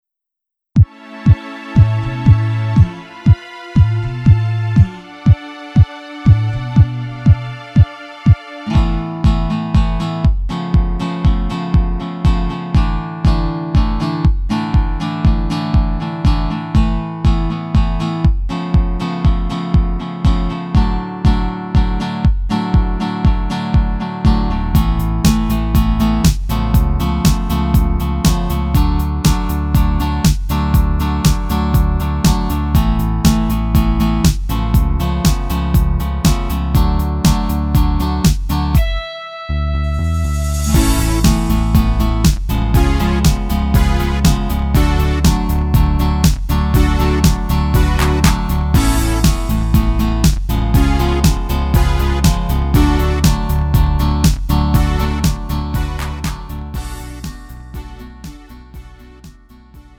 음정 -1키 3:33
장르 가요 구분 Lite MR